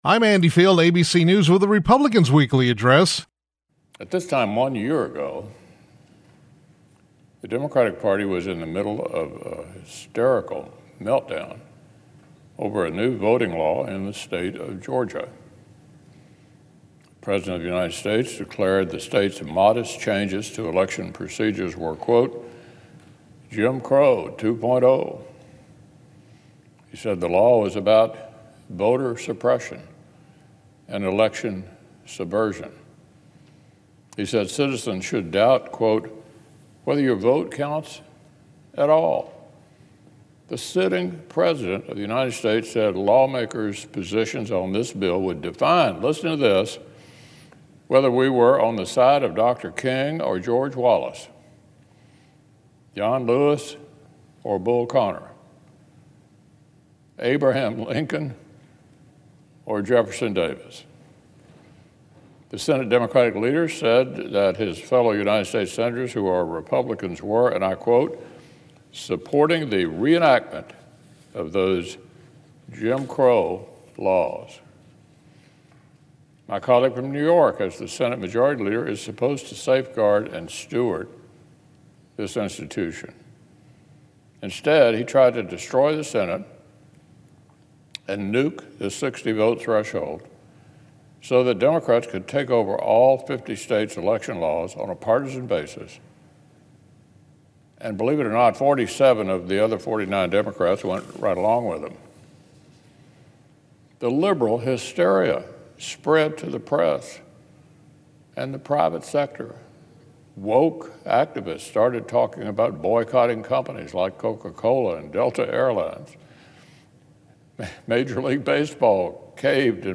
U.S. Senate Republican Leader Mitch McConnell (R-KY) recently delivered remarks on the Senate floor regarding voting laws.